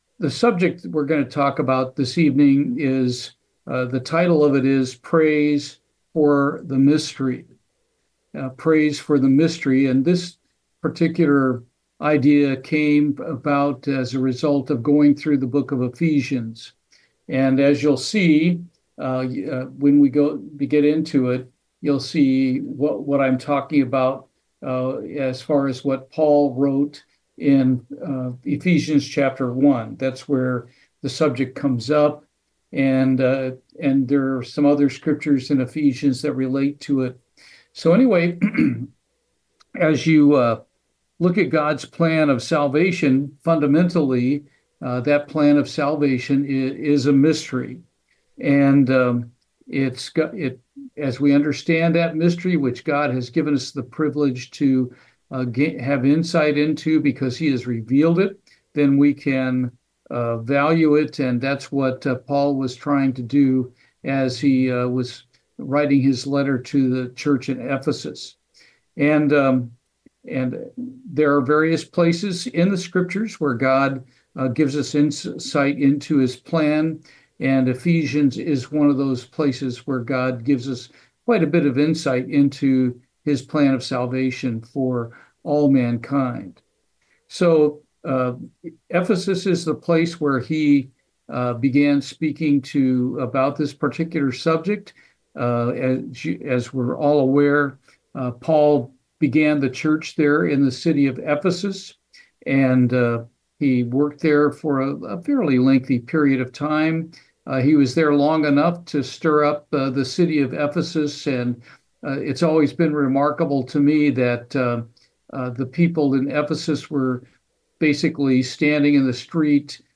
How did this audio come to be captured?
Given in Houston, TX